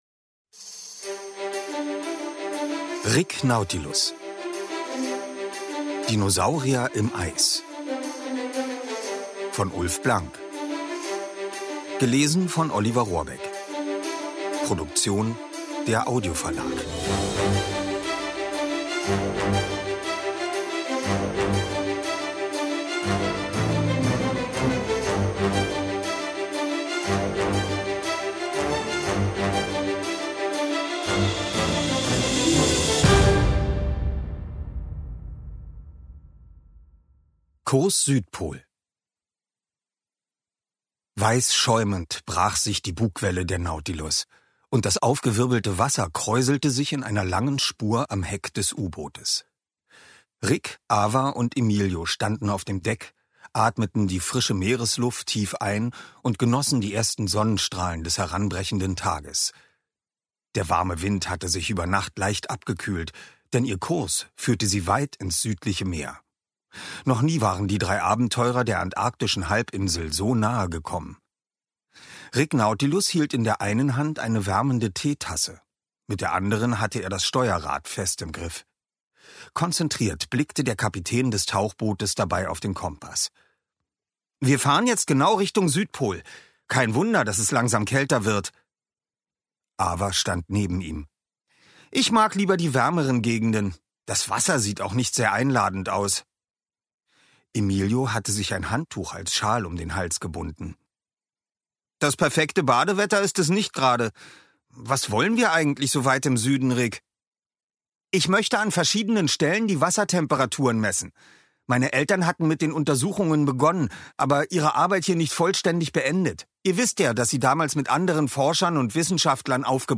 Rick Nautilus – Teil 6: Dinosaurier im Eis Ungekürzte Lesung mit Musik
Oliver Rohrbeck (Sprecher)